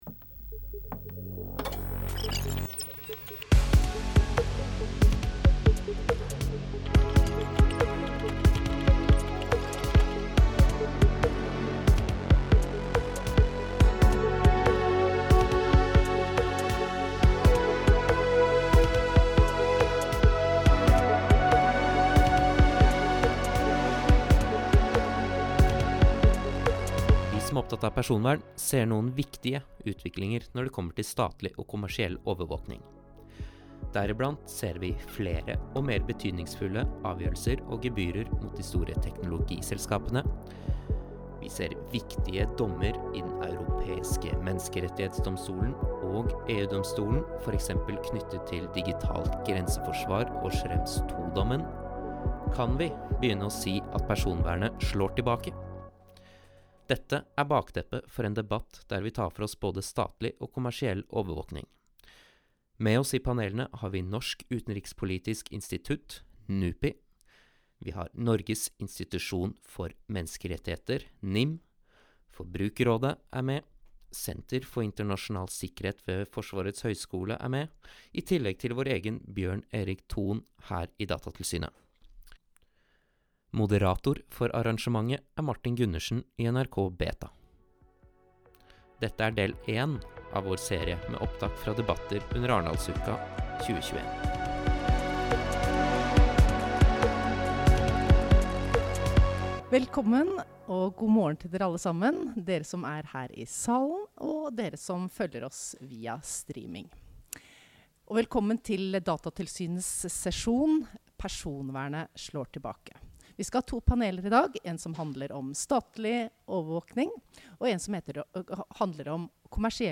Dette er et opptak fra et av våre arrangementer under Arendalsuka 2021.